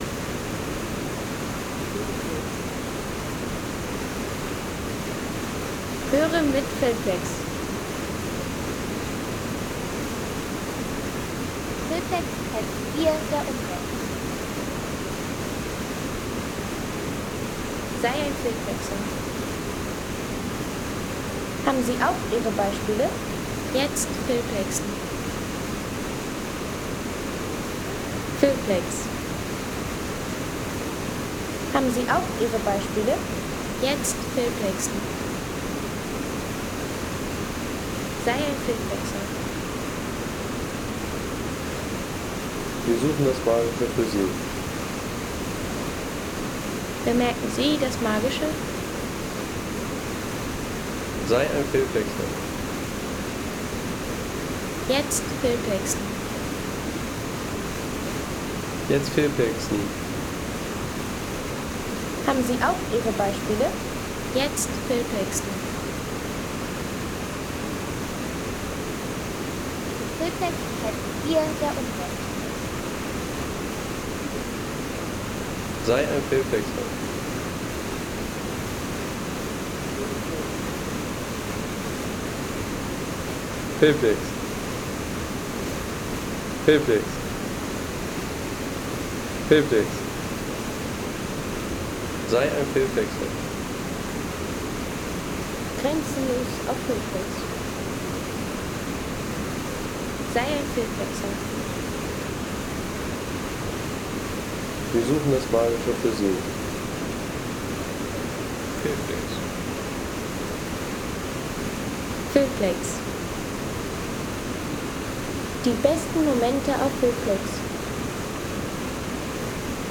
Stuibenfall Tirol Home Sounds Landschaft Wasserfälle Stuibenfall Tirol Seien Sie der Erste, der dieses Produkt bewertet Artikelnummer: 159 Kategorien: Landschaft - Wasserfälle Stuibenfall Tirol Lade Sound.... Majestätischer Stuibenfall – Tirols Größter Wasserfall in voller ... 3,50 € Inkl. 19% MwSt.